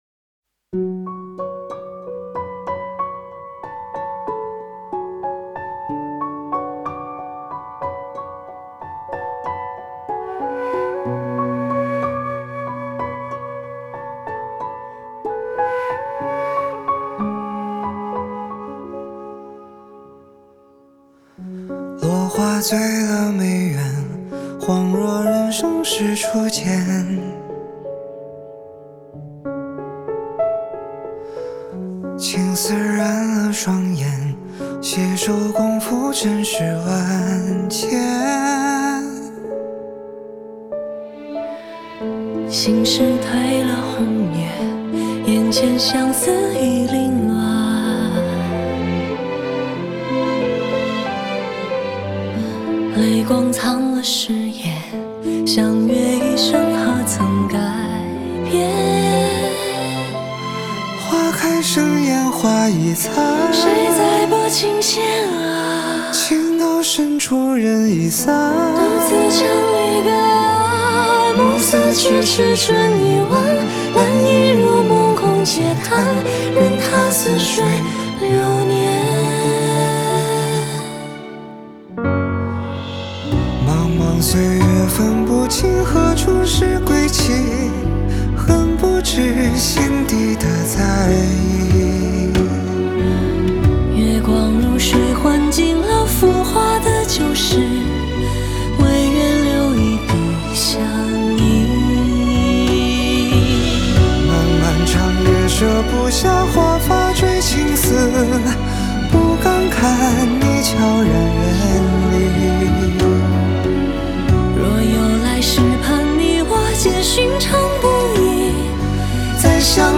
Ps：在线试听为压缩音质节选，体验无损音质请下载完整版
弦乐 Strings
笛子 DiZi